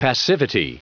Prononciation du mot passivity en anglais (fichier audio)
Prononciation du mot : passivity